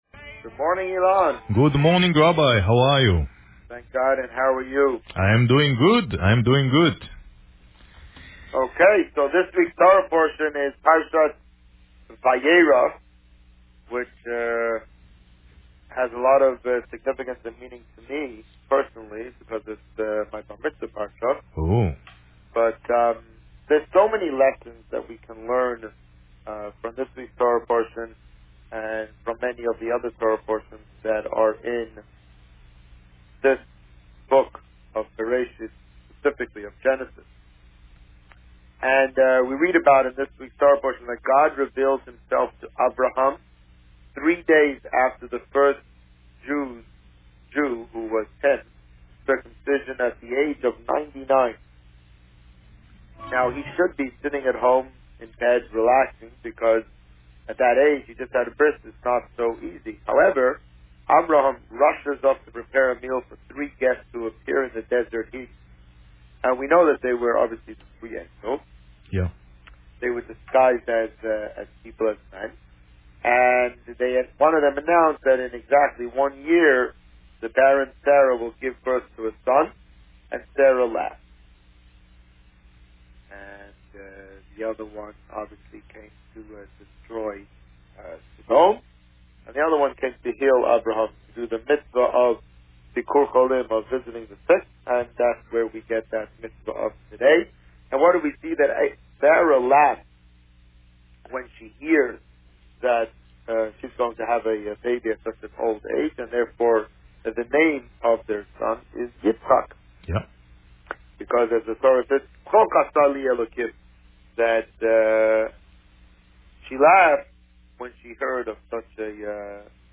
This week, the Rabbi spoke about Parsha Vayeira and hinted at the theme for this year's Chanukah menorah lighting. Listen to the interview here.